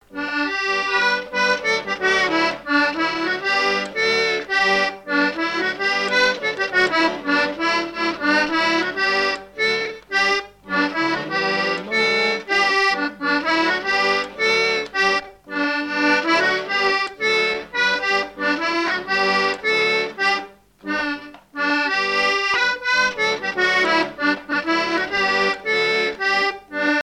danse : branle : courante, maraîchine
Genre laisse
Catégorie Pièce musicale inédite